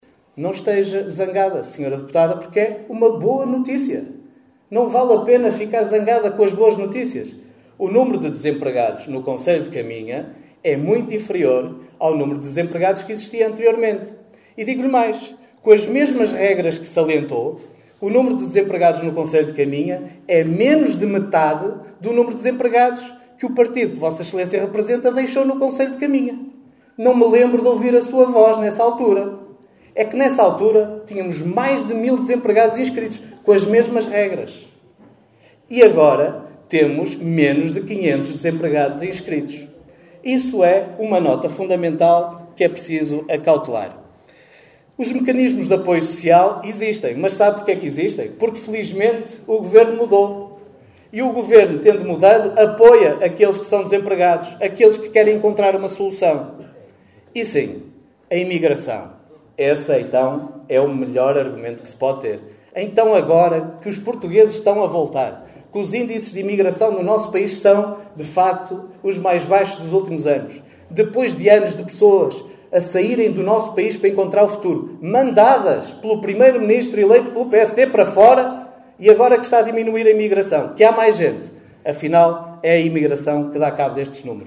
Extratos da última Assembleia Municipal de Caminha.